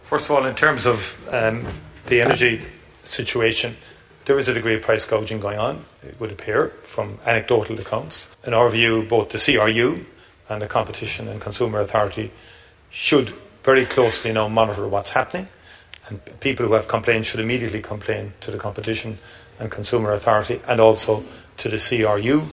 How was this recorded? The Taoiseach’s told the Dail there is price gouging happening in terms of fuel prices in Ireland.